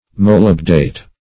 molybdate - definition of molybdate - synonyms, pronunciation, spelling from Free Dictionary Search Result for " molybdate" : The Collaborative International Dictionary of English v.0.48: Molybdate \Mo*lyb"date\, n. (Chem.) A salt of molybdic acid.